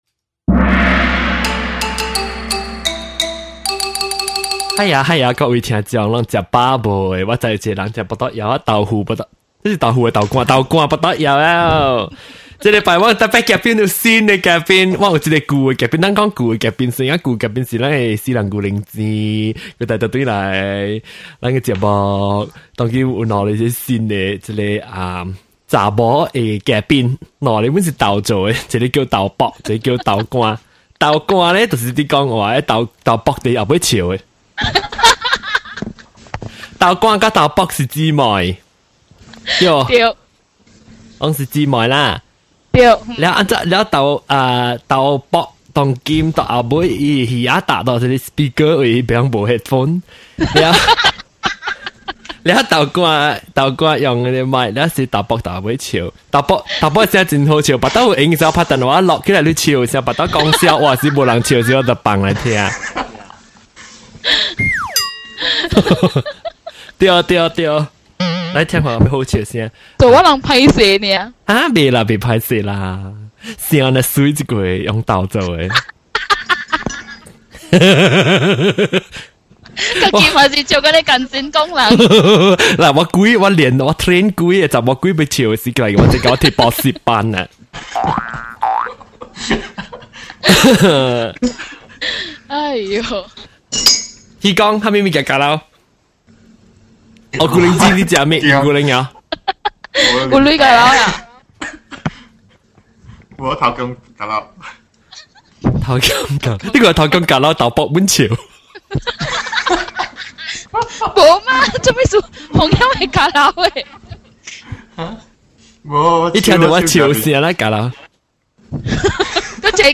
These pair of sisters are the model “laugher”.